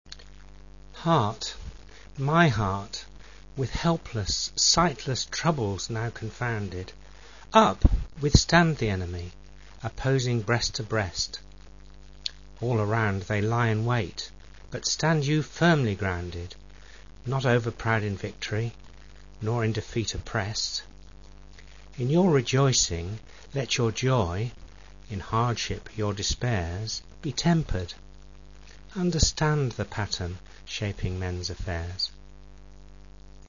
spoken version